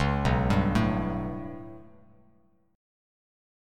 B7sus2#5 chord